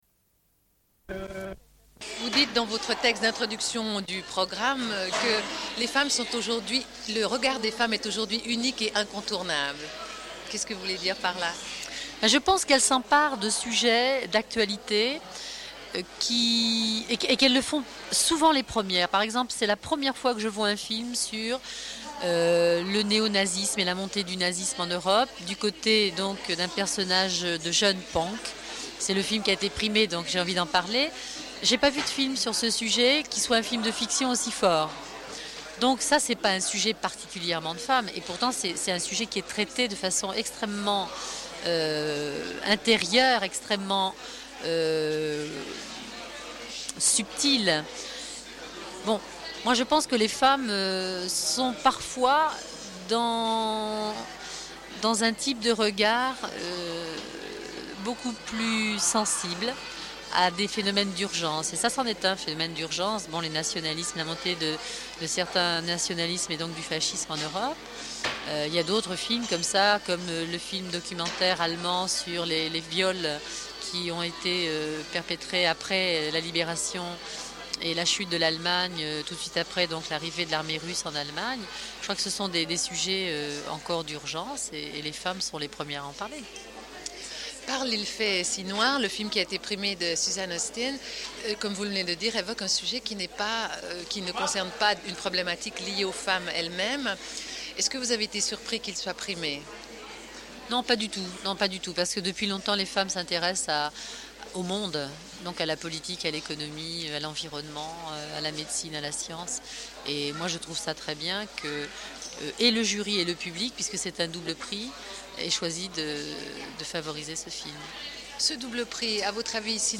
Une cassette audio, face A31:29